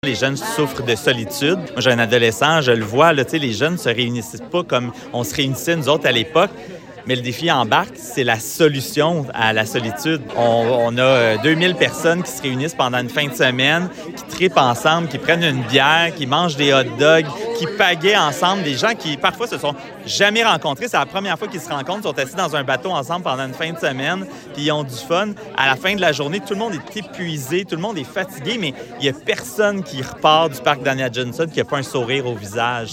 Le porte-parole de l’évènement, Jean-Philippe Dion.